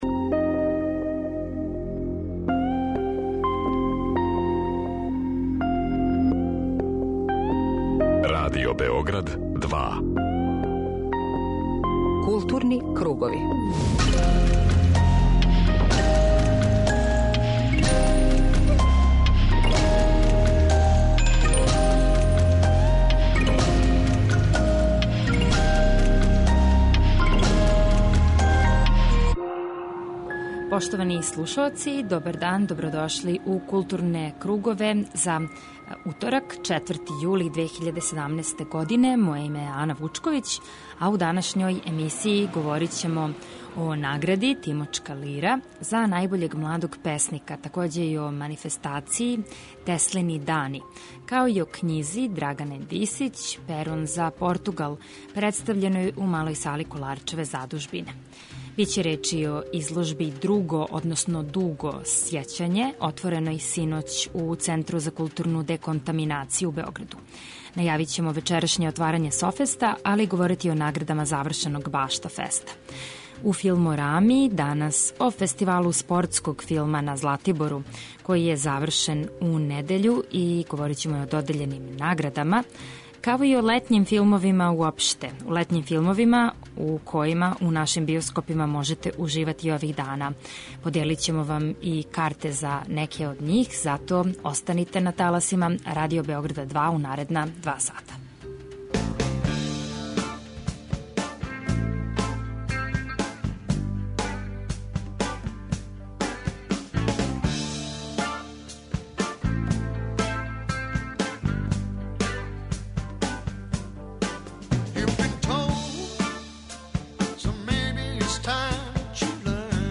Чућете и каква је атмосфера била ове године на фестивалу.